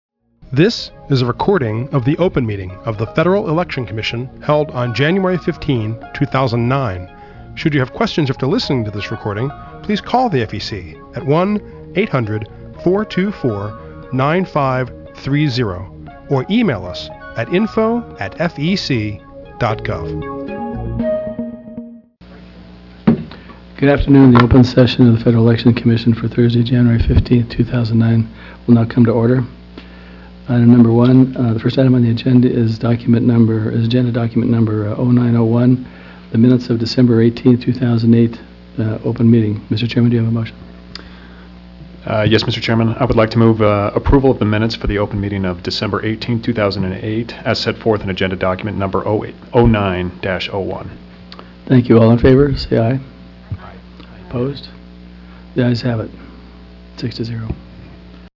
January 15, 2009 open meeting
The Commission considers new regulations, advisory opinions and other public matters at open meetings, which are typically held on Thursdays at 10:00 a.m. at FEC headquarters, 1050 First Street NE, Washington, DC.